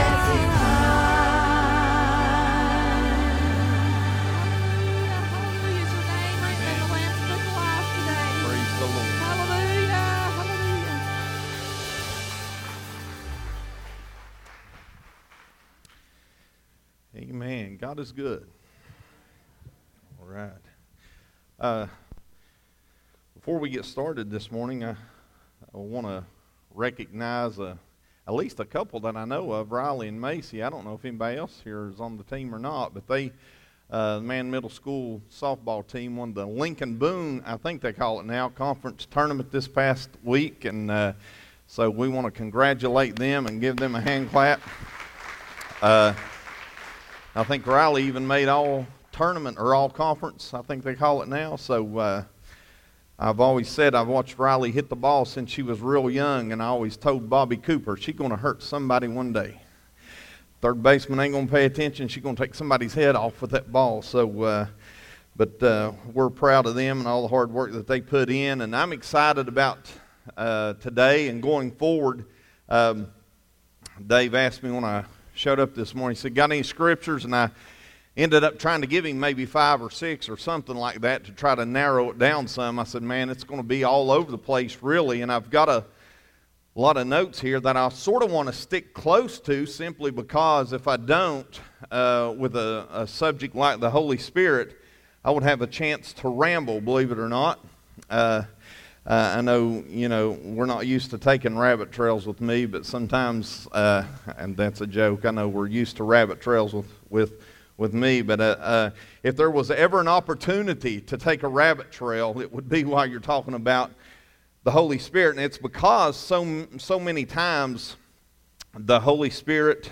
The Holy Spirit Sermon Series- Part 1 Audio